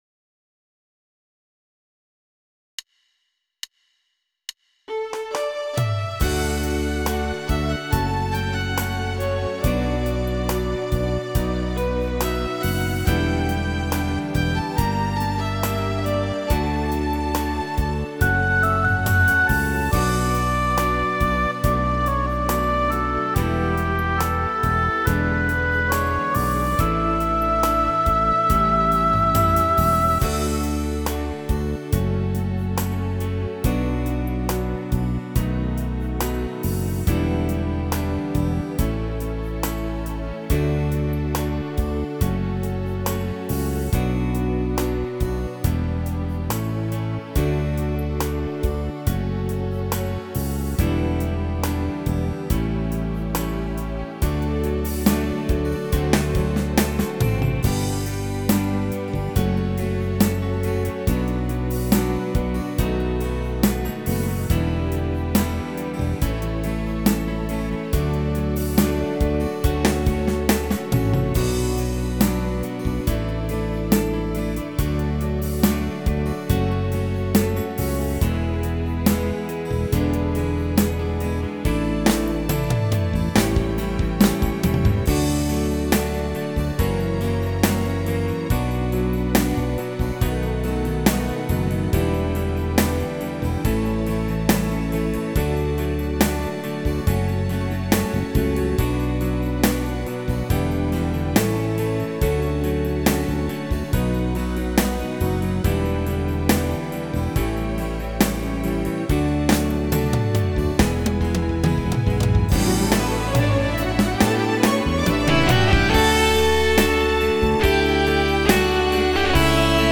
Tone Nữ (Bb) / Tốp (D)
•   Beat  03.